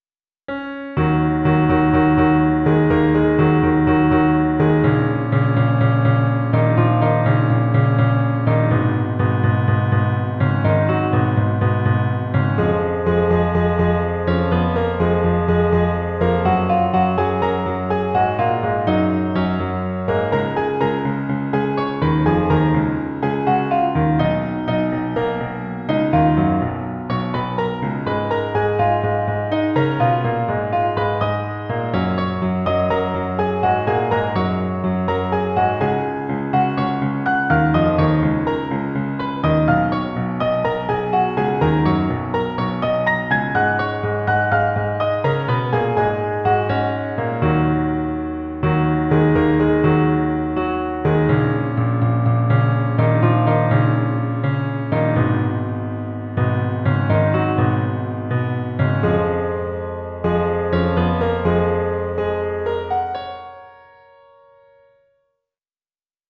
PIANO H-P (34)